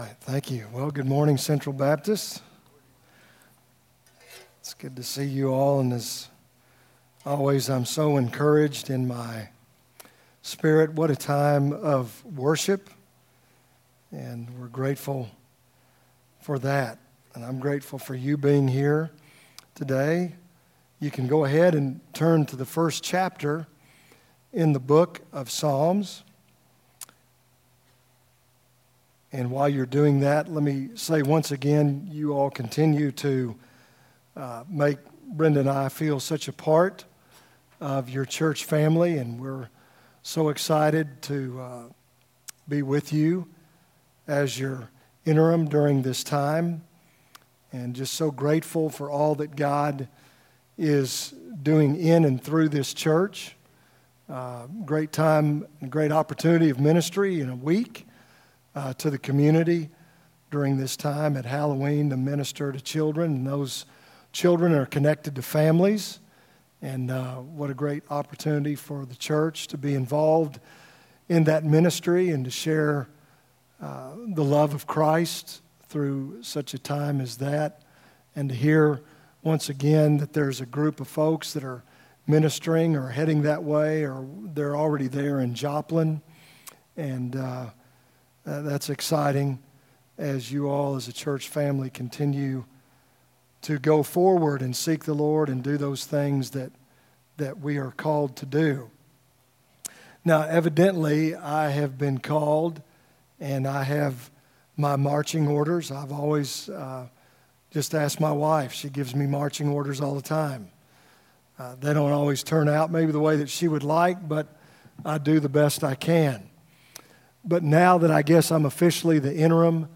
From Series: "2018 Sermons"